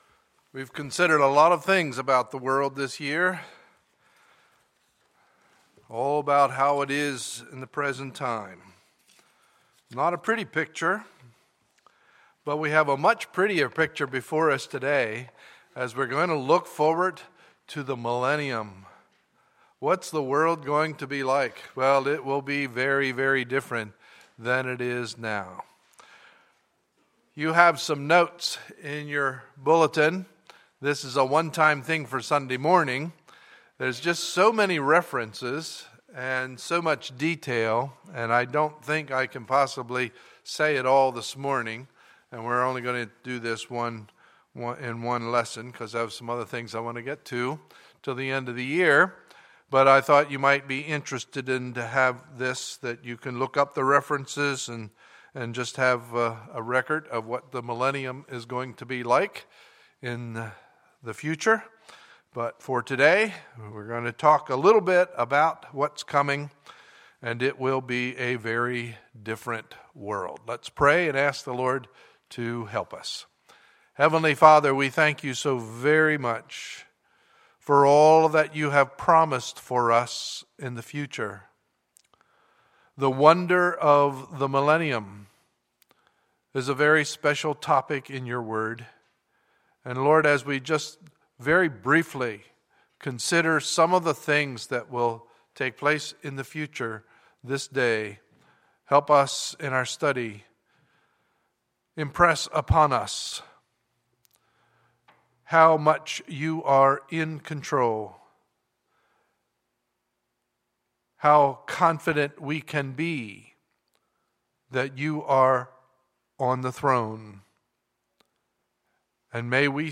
Sunday, November 9, 2014 – Sunday Morning Service